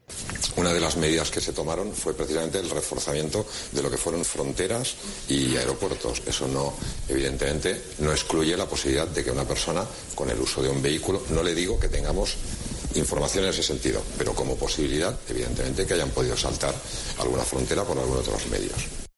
El mayor de los Mossos d'Esquadra, Josep Lluís Trapero